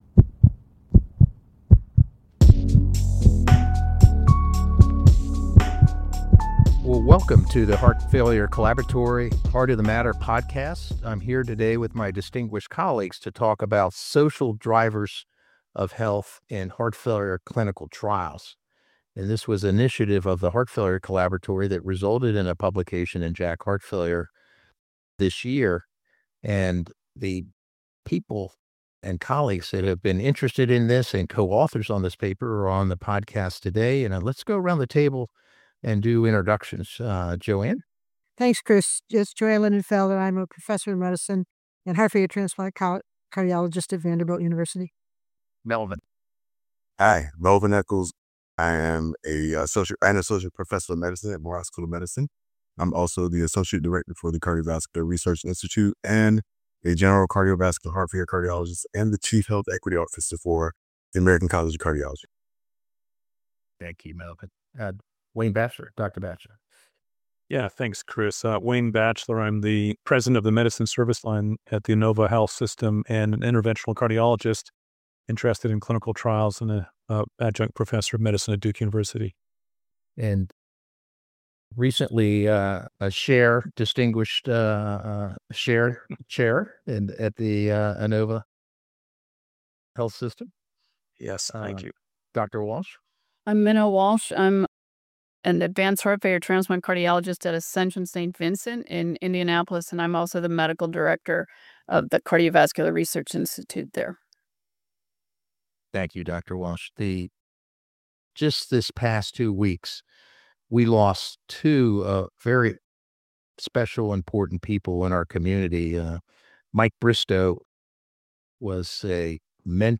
High-level conversations to foster conversation, collaboration, and communication around the ecosystem of cardiovascular research.